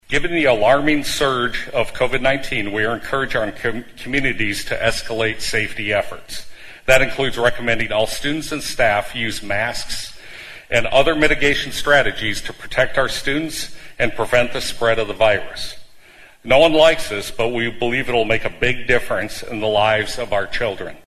Representatives from Sanford and Avera Health and the Sioux Falls Public Health Department held a joint news conference to outline their concerns about the surge in COVID infections.